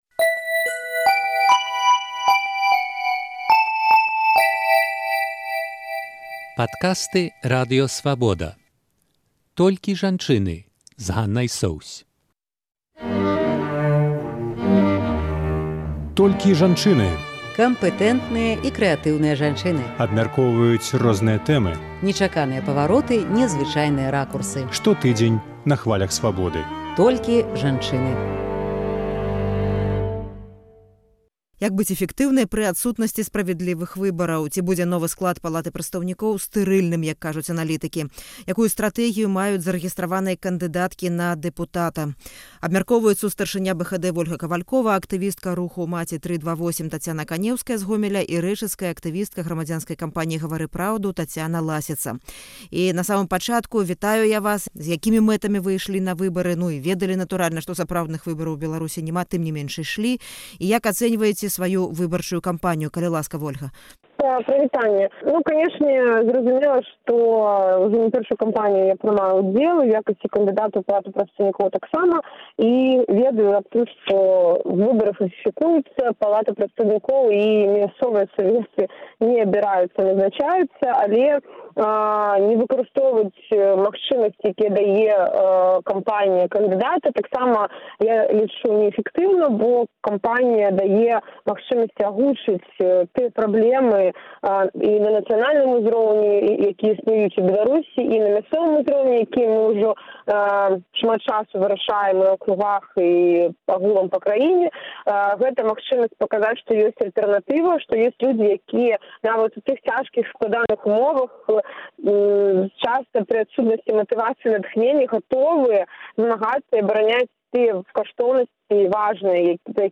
Ці будзе новы склад Палаты прадстаўнікоў стэрыльным? Якія магчымасьці маюць прызначаныя дэпутаты ў парлямэнце? Абмяркоўваюць тры зарэгістраваныя кандыдаткі ў дэпутаты.